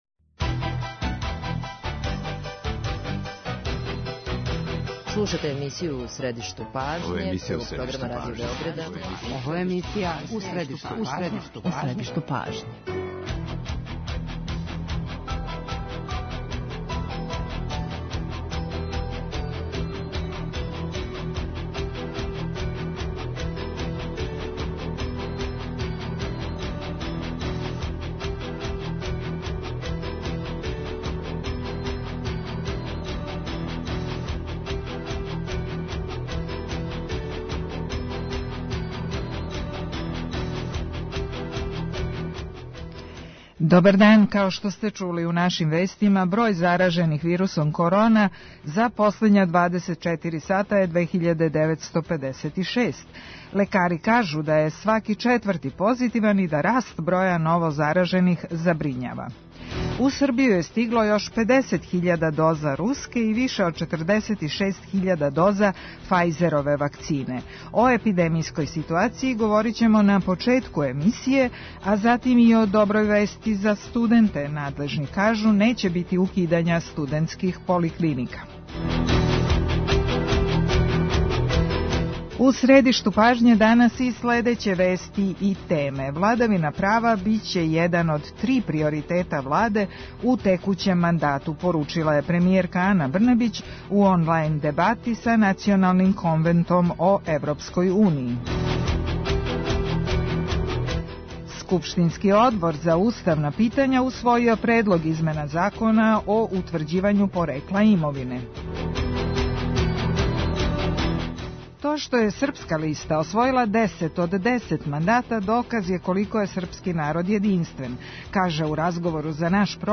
интервју